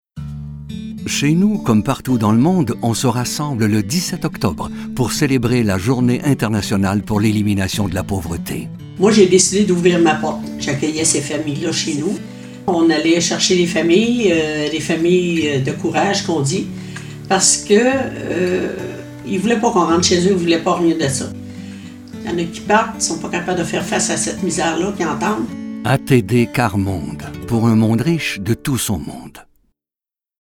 Au cours de l’émission, les spots annonçant le 17 Octobre réalisés pour les radios canadiennes ont également été diffusés.